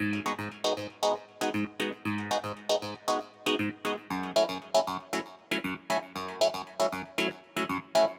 04 Clavinet PT3.wav